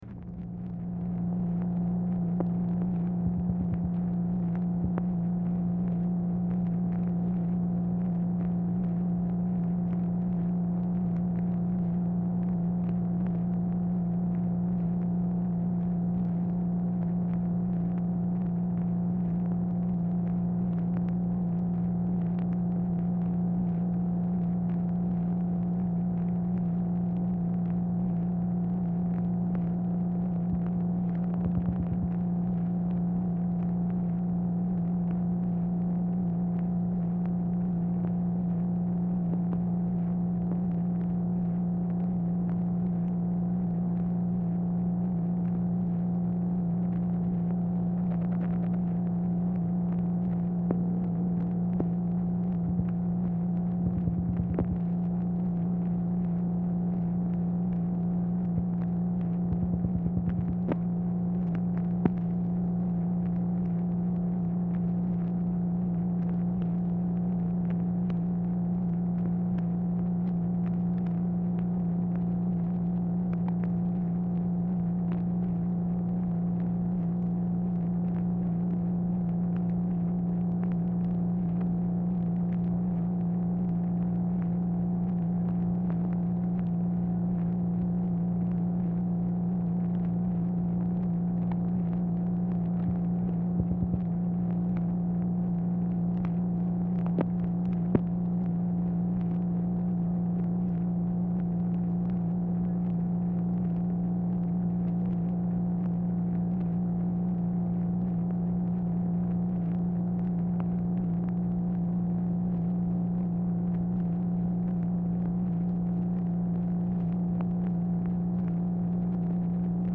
Telephone conversation # 9435, sound recording, MACHINE NOISE, 1/6/1966, time unknown | Discover LBJ
Format Dictation belt